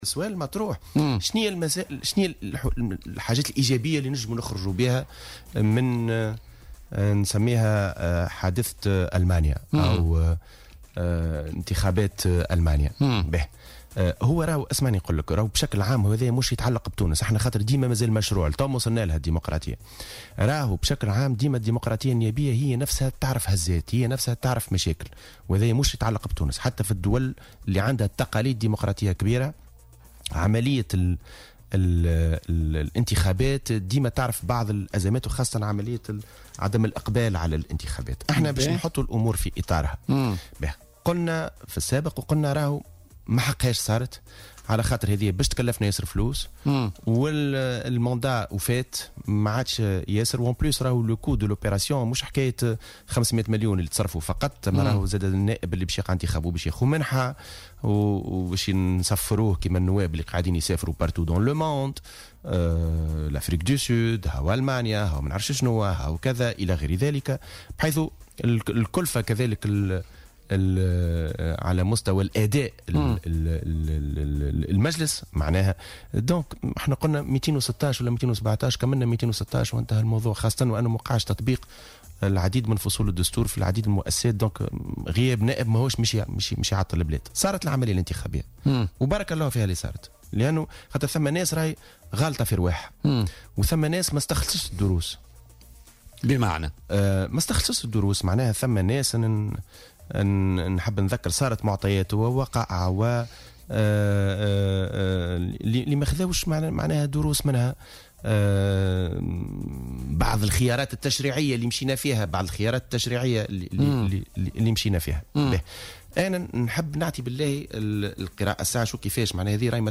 وقال في مداخلة له اليوم في برنامج "بوليتيكا" إن هذه الانتخابات كانت مكلفة جدّا ودون جدوى ( كلفة إجراء الانتخابات، إضافة إلى المنح التي سيتمتع بها النائب)، وفق تعبيره. وأضاف أن مراجعة هذه التمثيلية والتقليص أيضا في عدد النواب ممكن في إطار القانون.